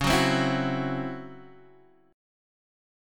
DbmM7bb5 chord